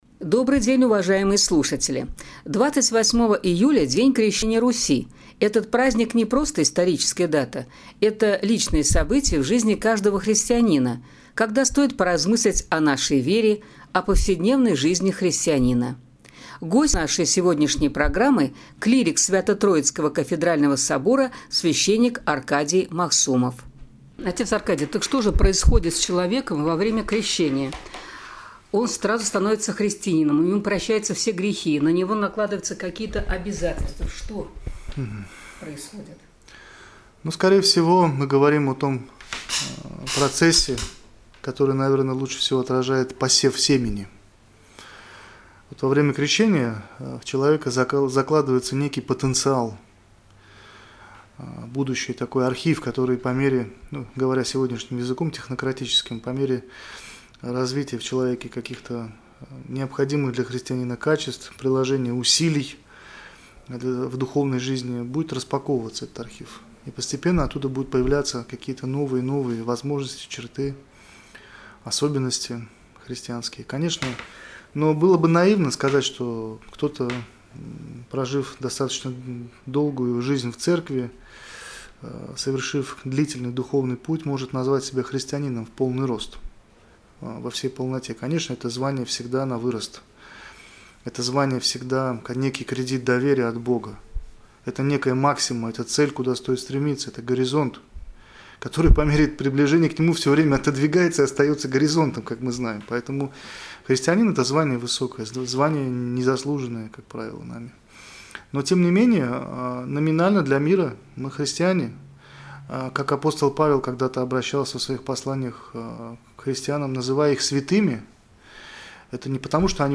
Стать христианином. Беседа